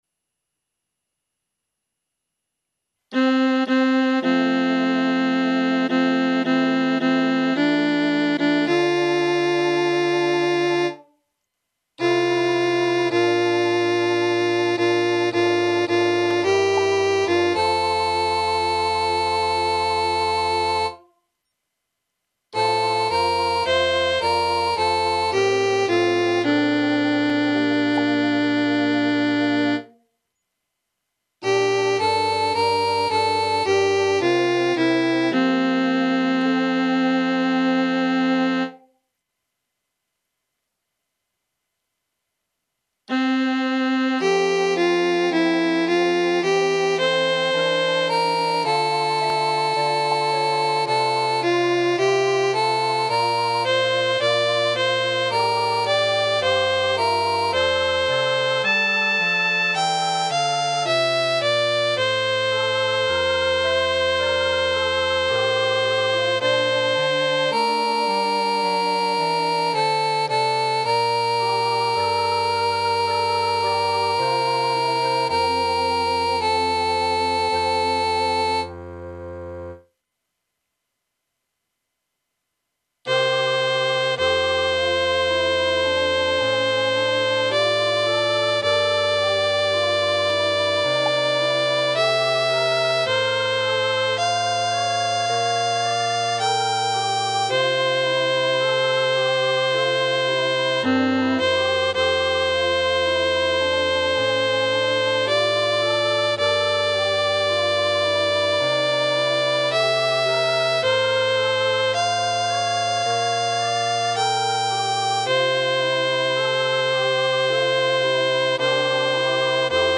vierstemmig gemengd zangkoor